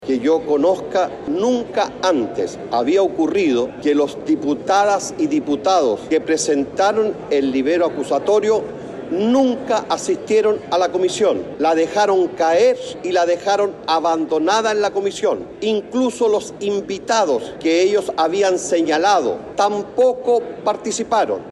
“Que yo conozca, nunca antes había ocurrido que los diputados que presentaron el libelo acusatorio nunca asistieron a la Comisión”, apuntó el diputado y presidente de la comisión revisora, Jaime Naranjo.